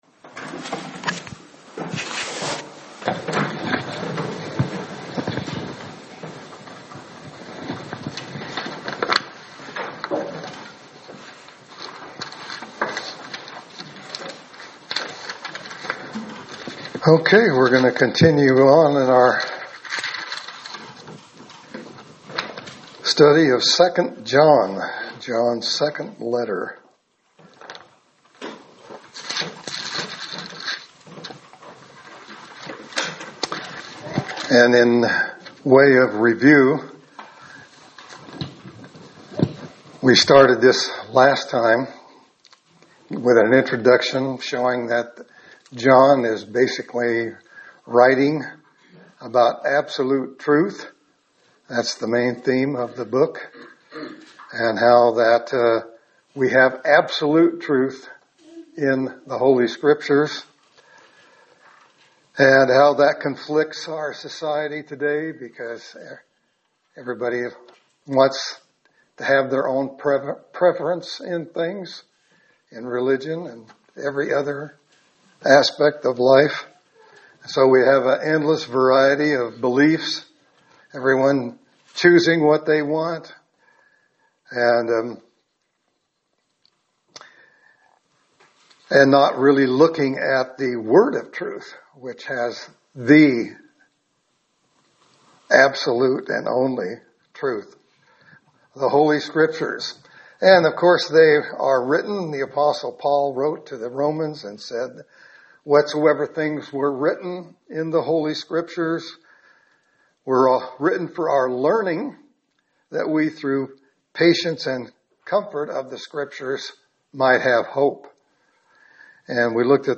Sermon for June 29, 2025